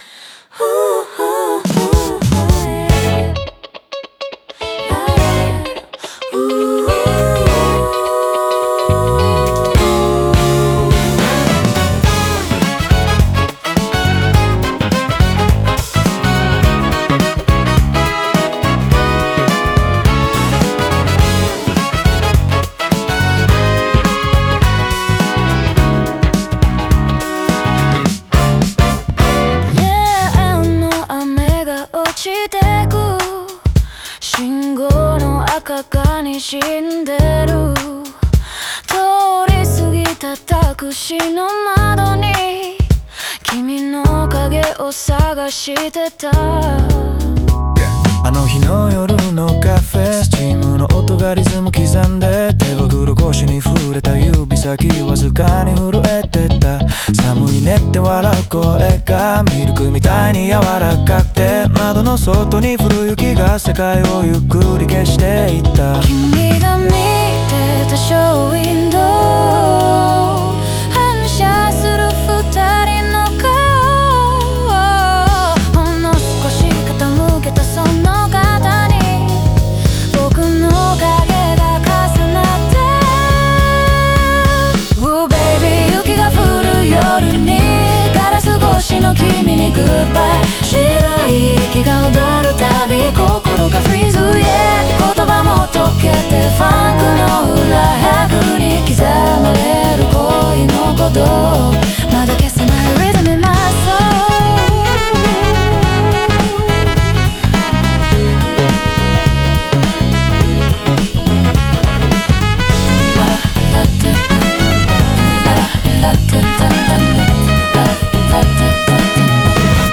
オリジナル曲♪
ファンクのビートは、心の動揺と未練のリズムを象徴し、熱いグルーヴの中に冷たい孤独が潜む。